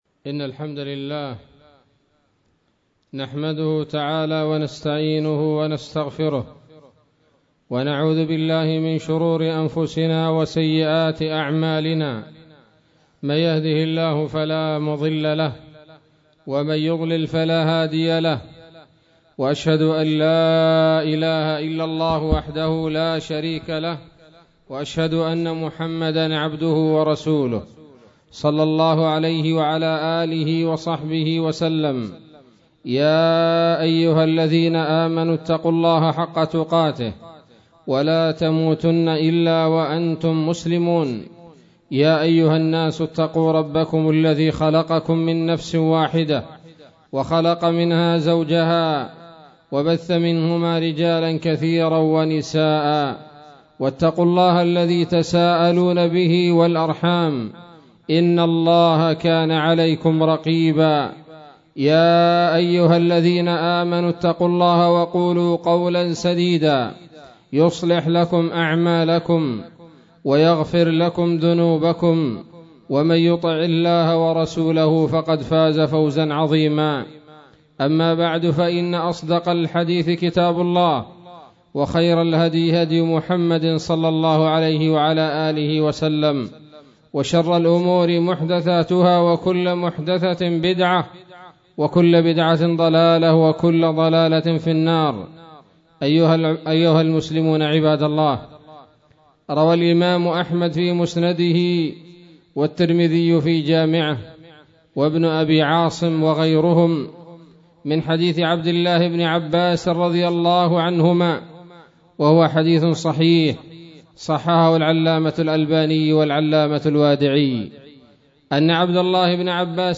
خطبة بعنوان : ((احفظ الله يحفظك)) 13 شعبان 1437 هـ